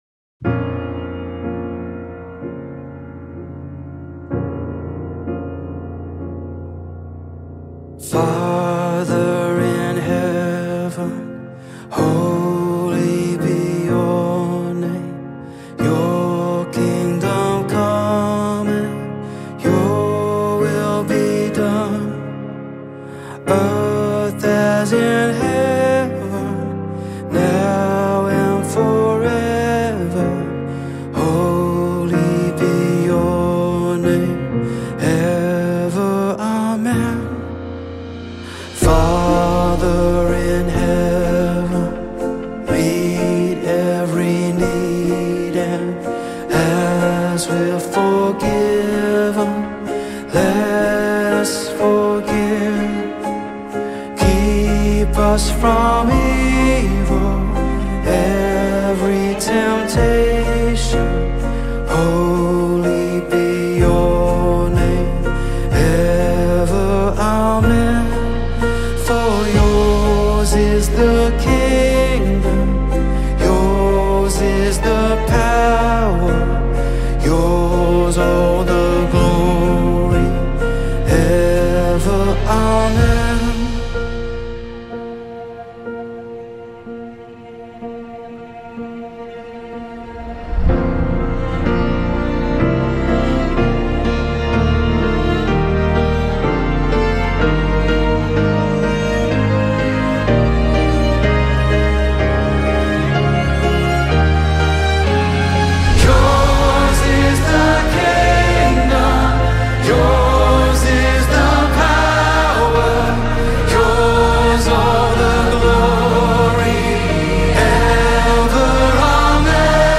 44 просмотра 52 прослушивания 4 скачивания BPM: 125